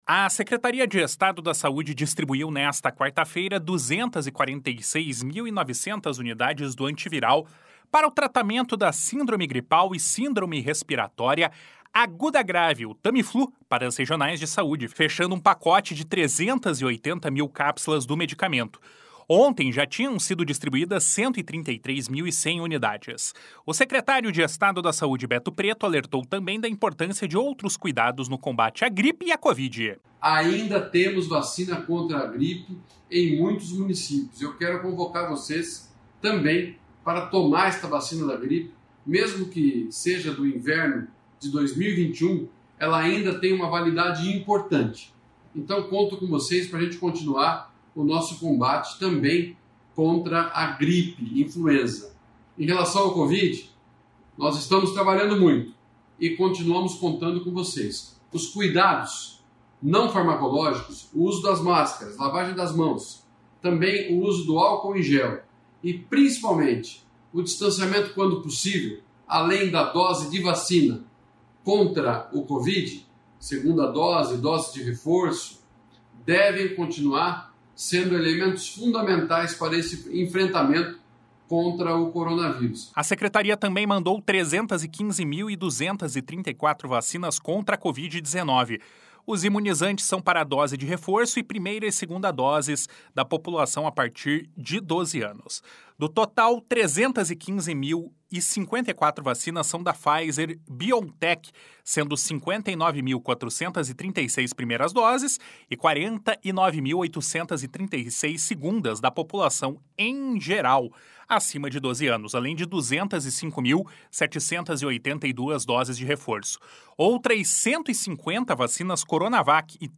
O secretário de Estado da Saúde, Beto Preto, alertou também da importância de outros cuidados no combate à gripe e à Covid. // SONORA BETO PRETO //